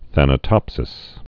(thănə-tŏpsĭs)